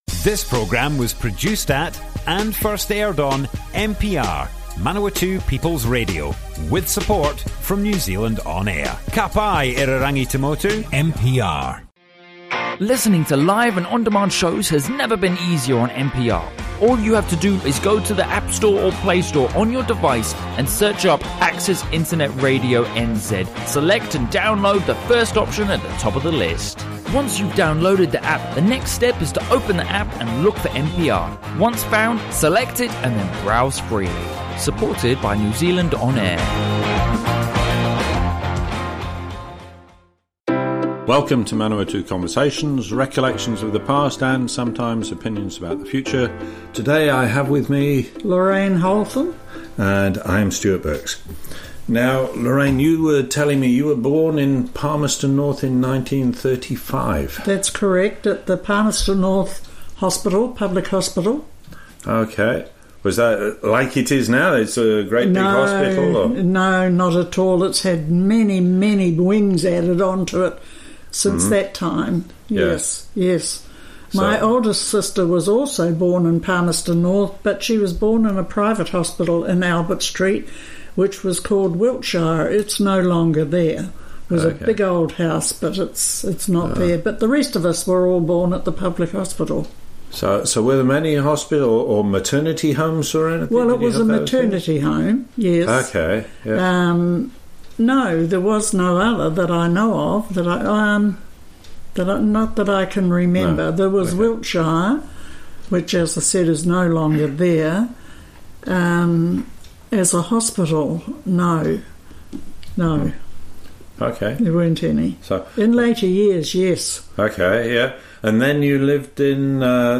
Manawatu Conversations More Info → Description Broadcast on Manawatu People's Radio 5th February 2019.
oral hiostory